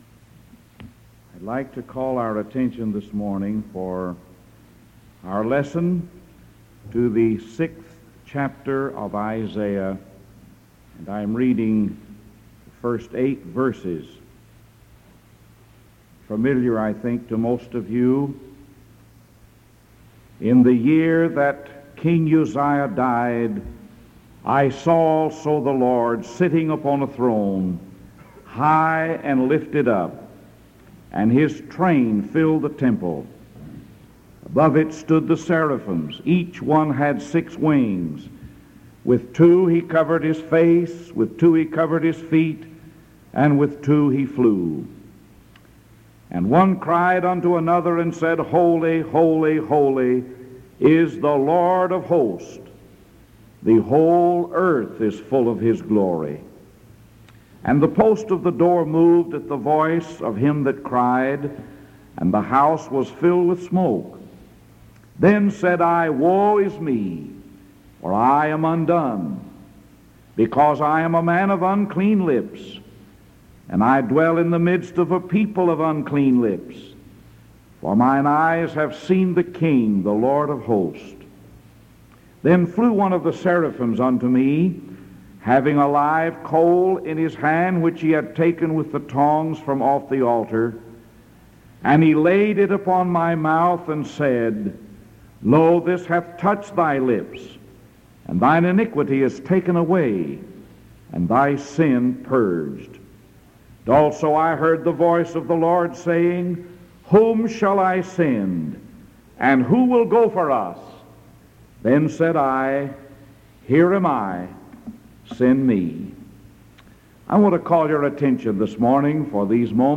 Sermon August 5th 1973 AM